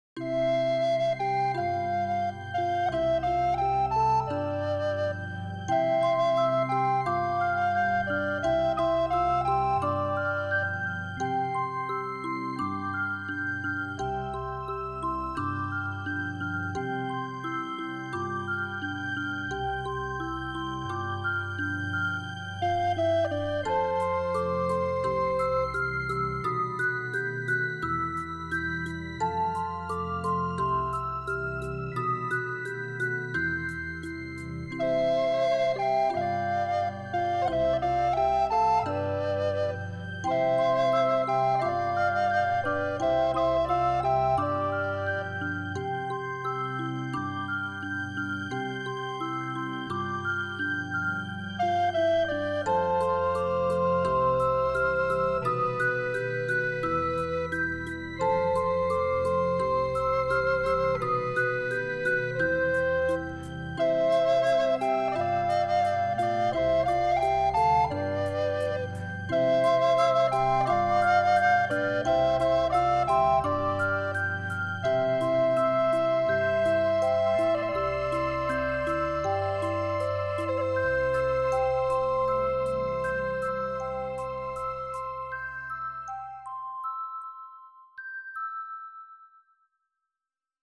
voce e pf.
base musicale [bambini]